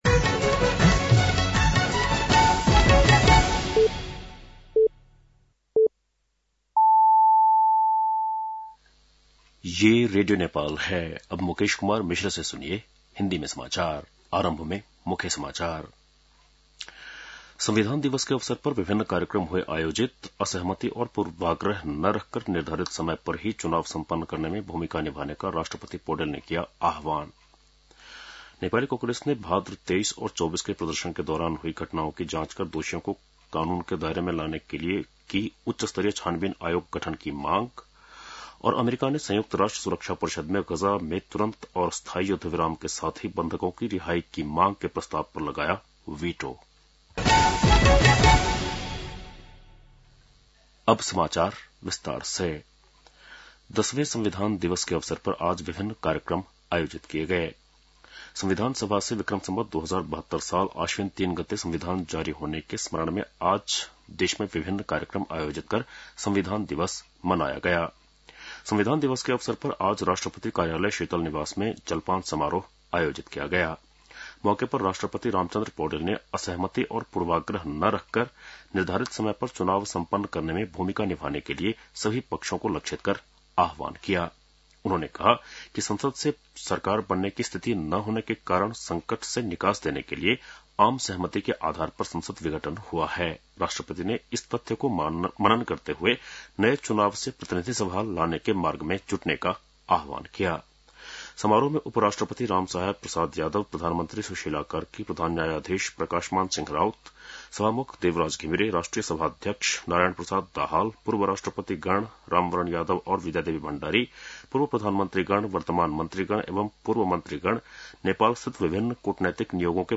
बेलुकी १० बजेको हिन्दी समाचार : ३ असोज , २०८२